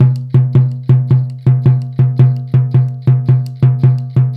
Index of /90_sSampleCDs/Spectrasonics - Supreme Beats - World Dance/BTS_Tabla_Frames/BTS_Frame Drums